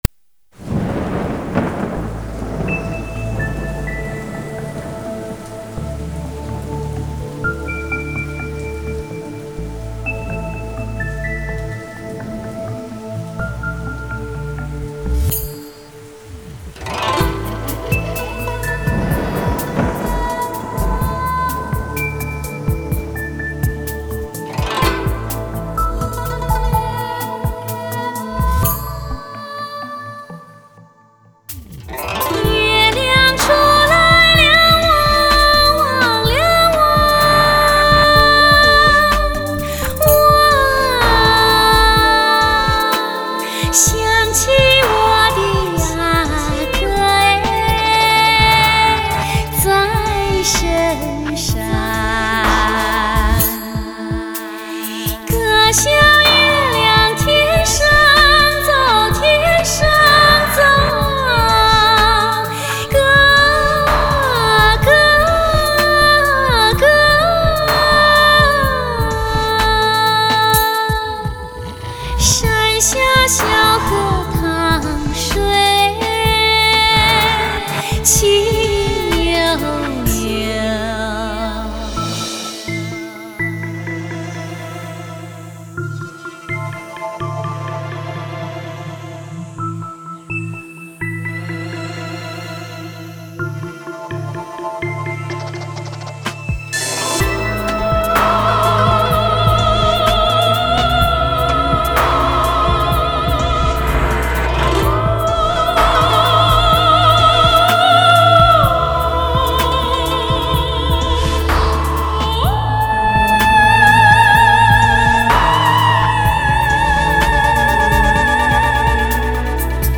专辑类型：dts ES6.1黑胶
德国黑胶唱片 dts环绕声测试碟～
真的好棒  声音真的好像在 耳边绕着